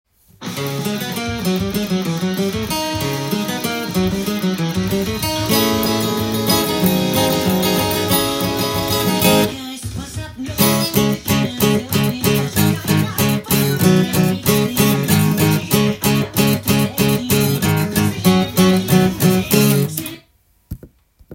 音源にあわせて譜面通り弾いてみました
この曲は、カポ４で弾くと譜面通りに弾くことが出来ます。
クロマチックスケールを混ぜたエキゾチックな